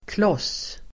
Ladda ner uttalet
Uttal: [klås:]